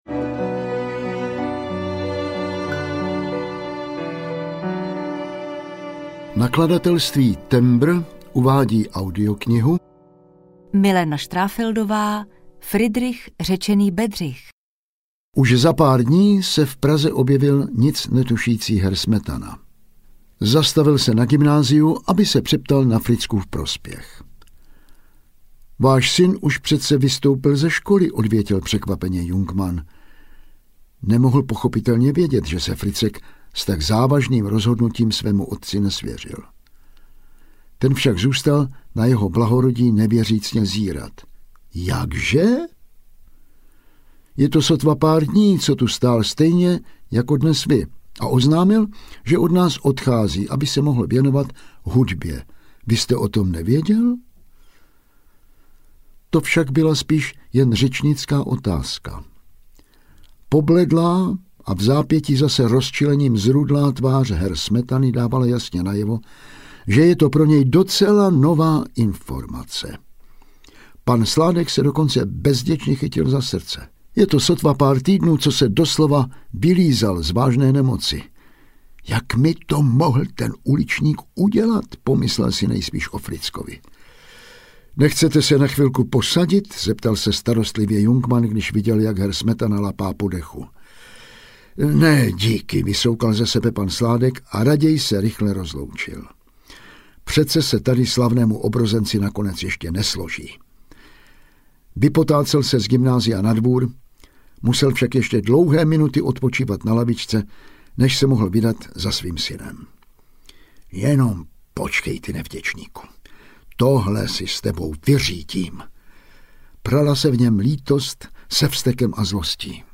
Friedrich řečený Bedřich audiokniha
Ukázka z knihy